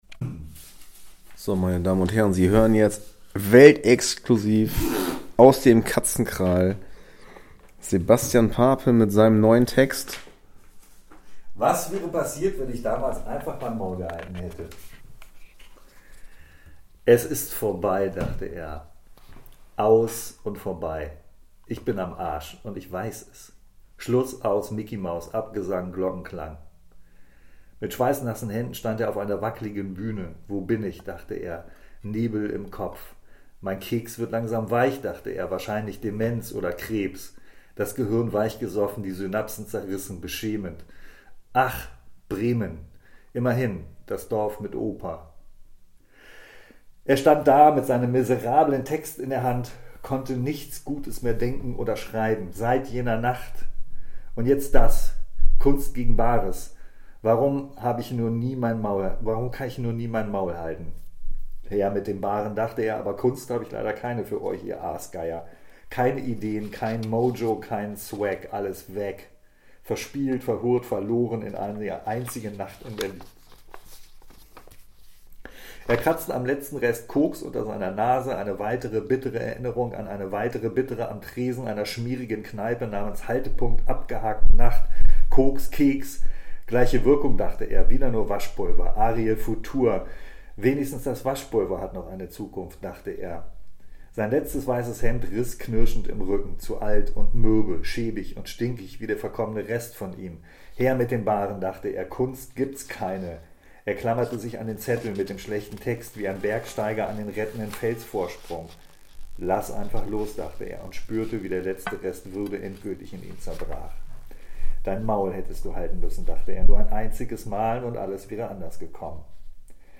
Exklusive Kurzlesung.
Comedy